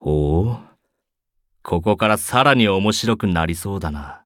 文件 文件历史 文件用途 全域文件用途 Ja_Bhan_amb_05.ogg （Ogg Vorbis声音文件，长度4.3秒，103 kbps，文件大小：54 KB） 源地址:游戏语音 文件历史 点击某个日期/时间查看对应时刻的文件。 日期/时间 缩略图 大小 用户 备注 当前 2018年5月25日 (五) 02:59 4.3秒 （54 KB） 地下城与勇士  （ 留言 | 贡献 ） 分类:巴恩·巴休特 分类:地下城与勇士 源地址:游戏语音 您不可以覆盖此文件。